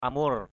amur.mp3